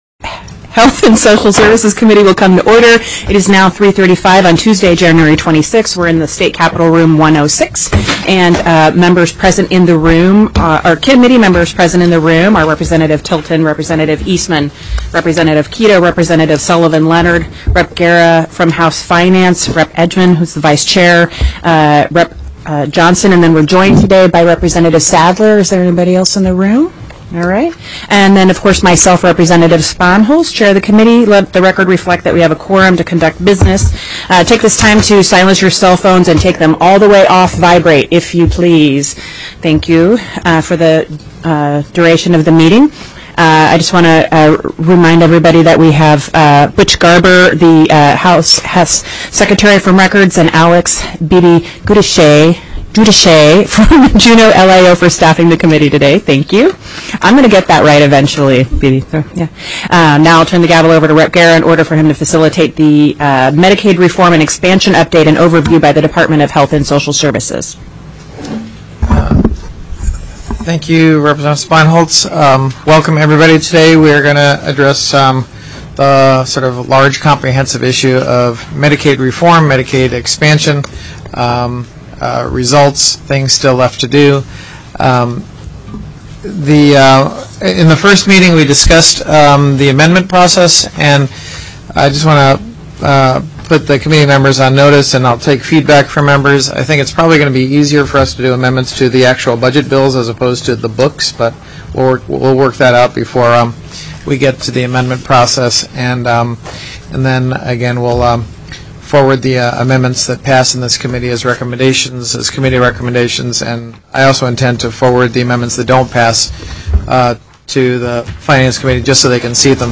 01/26/2017 03:00 PM House HEALTH & SOCIAL SERVICES
The audio recordings are captured by our records offices as the official record of the meeting and will have more accurate timestamps.